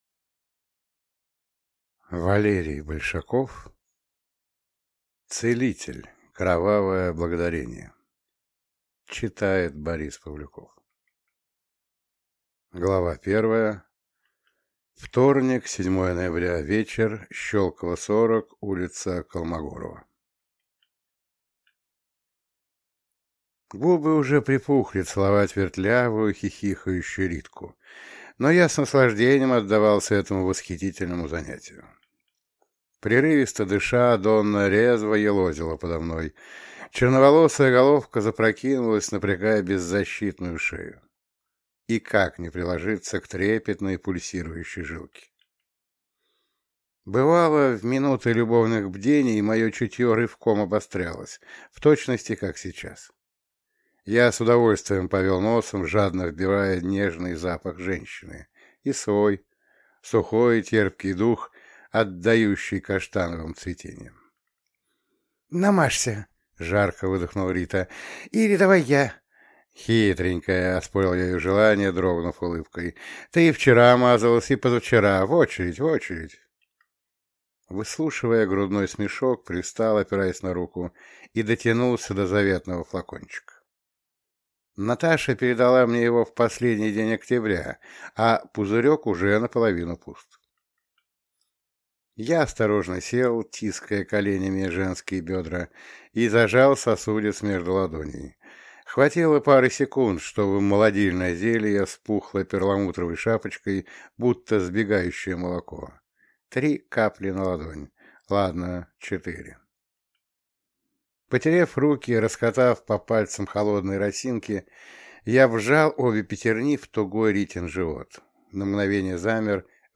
Чужой наследник (слушать аудиокнигу бесплатно) - автор Саша Фишер